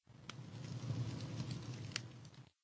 Minecraft Version Minecraft Version 1.21.5 Latest Release | Latest Snapshot 1.21.5 / assets / minecraft / sounds / block / campfire / crackle6.ogg Compare With Compare With Latest Release | Latest Snapshot
crackle6.ogg